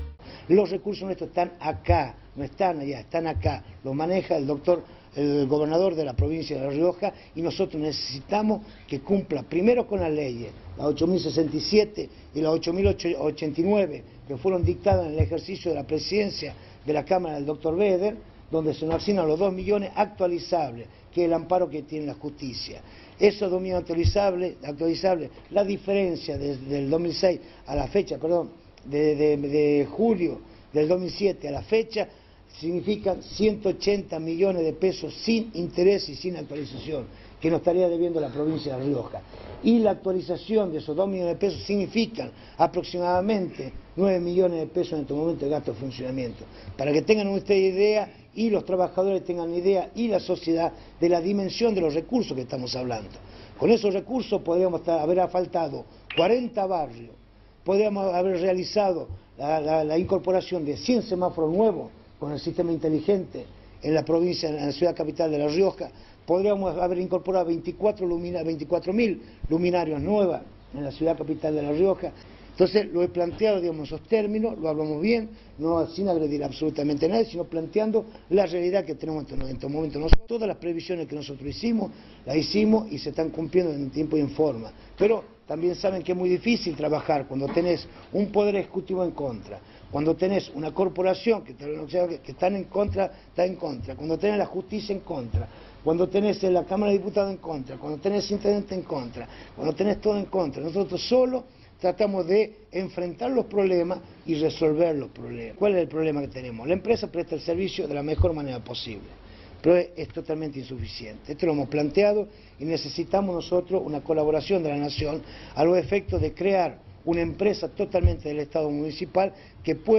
Ricardo Quintela, intendente de la capital riojana
Luego de la firma de convenio con la Universidad Barceló, el jefe comunal brindó una conferencia de prensa, en donde informó que tuvo la oportunidad de reunirse con autoridades de la Secretaría de Transporte, a quienes les planteo el problema que “tenemos acá”.